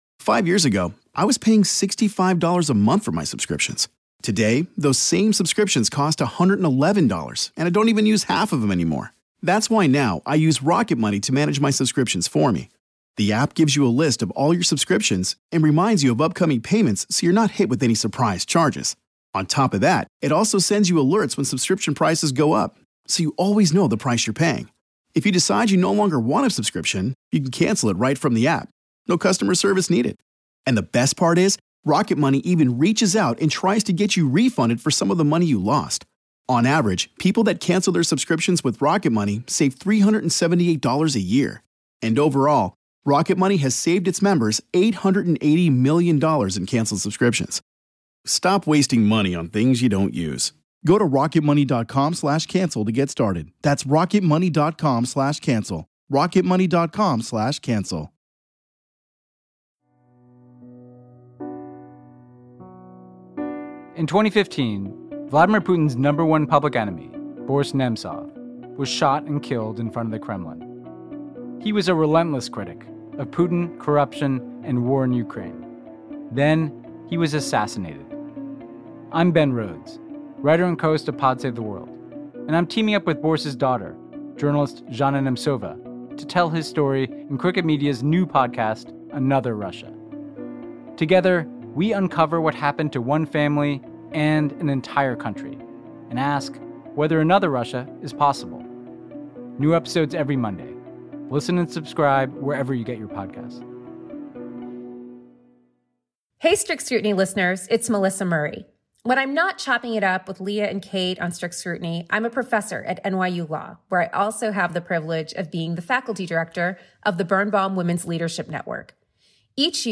In March, the NYU School of Law hosted the the Birnbaum Women’s Leadership Network's symposium on Politics, Power, and Women’s Leadership.